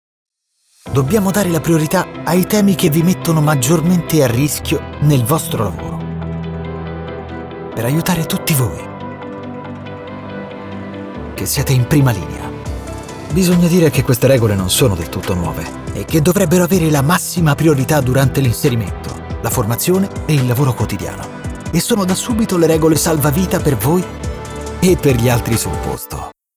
Commercieel, Zacht, Zakelijk, Speels, Veelzijdig
Corporate